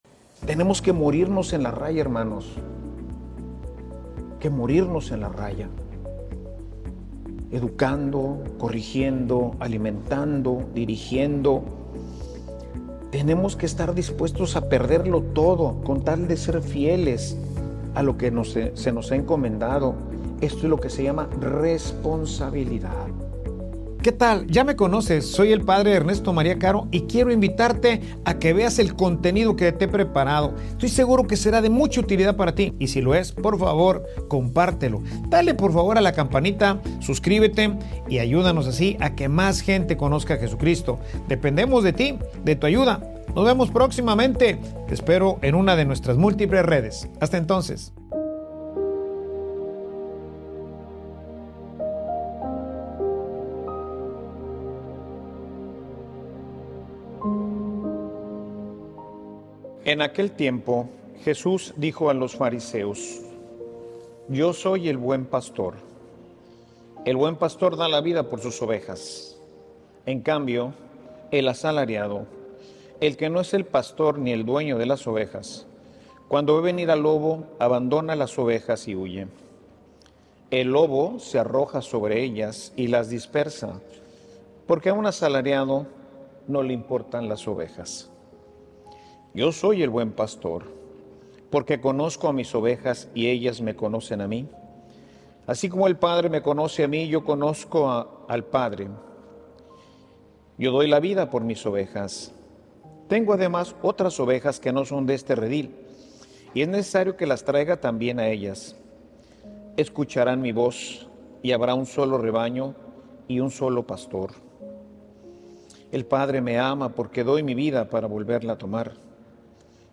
Homilia_Tu_amor_mide_tu_fidelidad.mp3